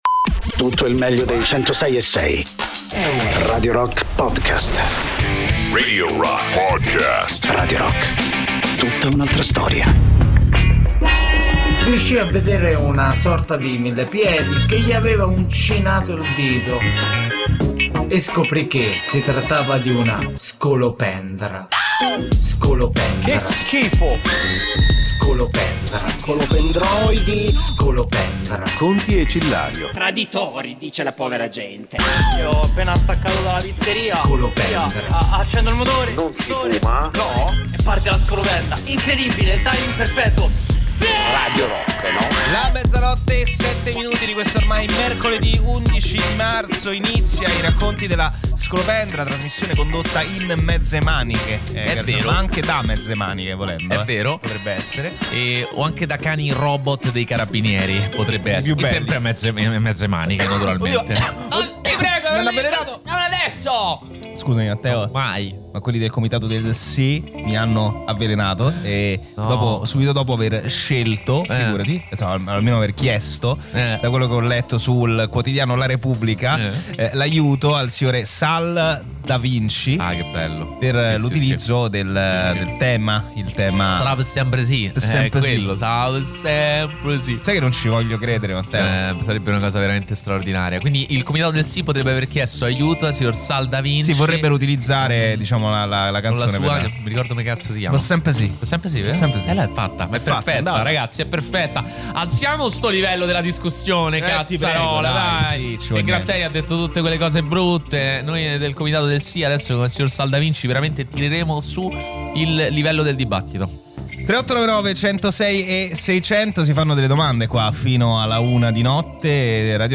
in diretta Sabato e Domenica dalle 15 alle 18